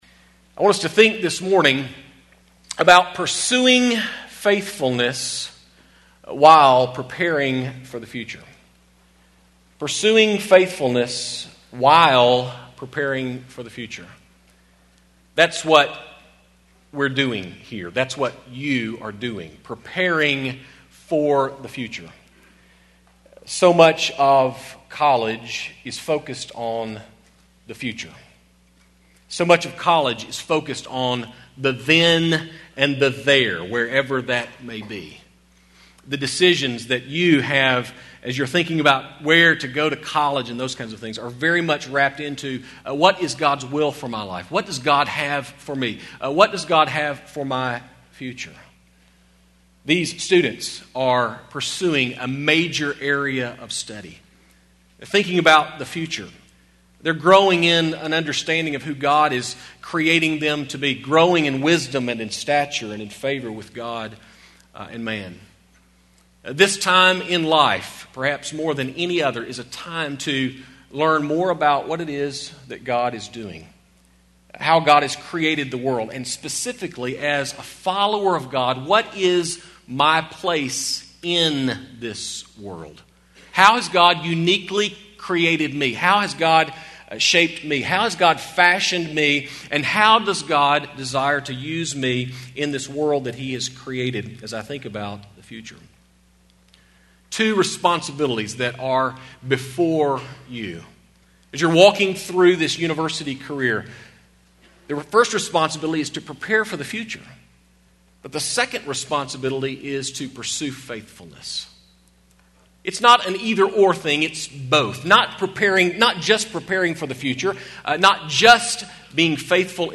Union University, a Christian College in Tennessee
Chapels